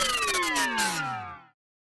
fall_death_speed.mp3